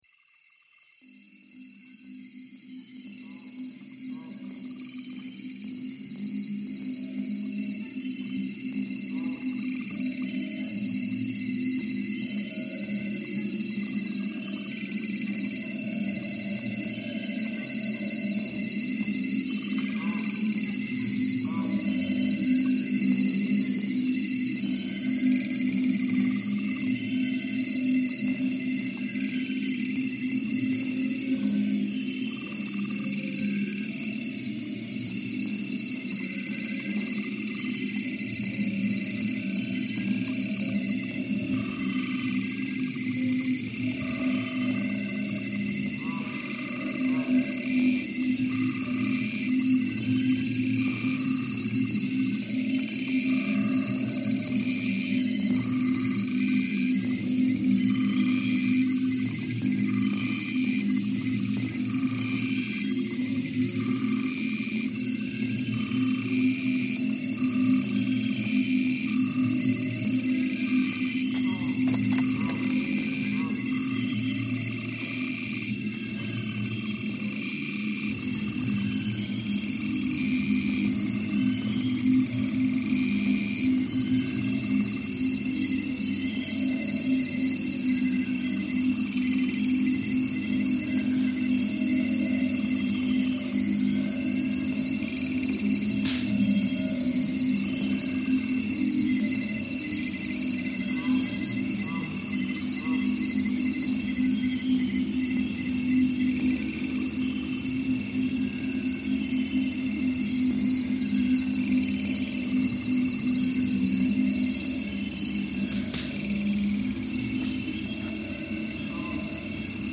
Night in the Ecuadorian Amazon reimagined